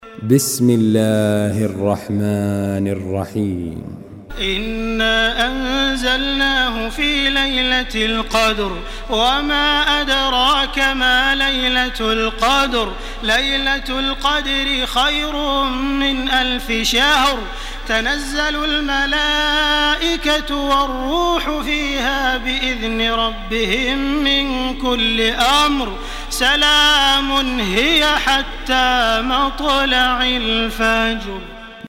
Surah Al-Qadr MP3 by Makkah Taraweeh 1429 in Hafs An Asim narration.
Murattal